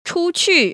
[//‧chū//‧qù] 추취